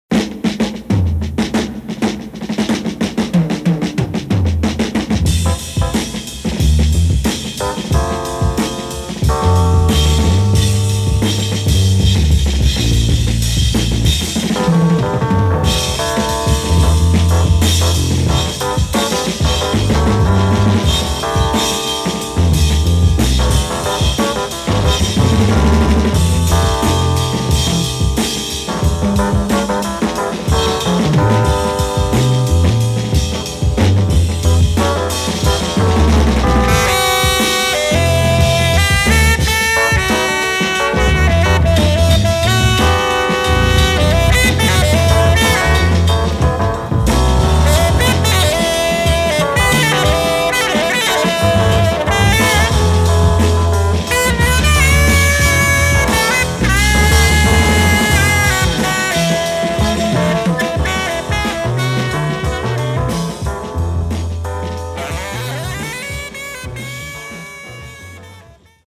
Tenor and soprano saxophones
Electric piano and moog synthesizer
Electric and acoustic basses
Drums, congas, bongos and bell